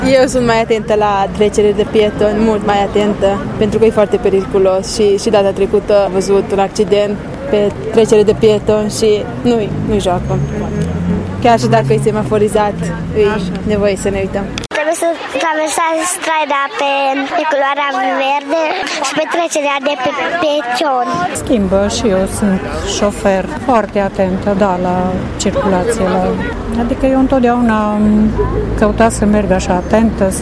Inspectoratul de Poliţie Judeţean Mureş a organizat aseară o manifestare dedicată memoriei victimelor decedate în accidente rutiere.
Târgumureşenii s-au oprit, au aprins o lumânare în memoria victimelor şi au plecat spre casă cu o atenţie sporită la volan sau pe trecerea de pietoni: